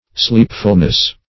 -- Sleep"ful*ness , n. [1913 Webster]
sleepfulness.mp3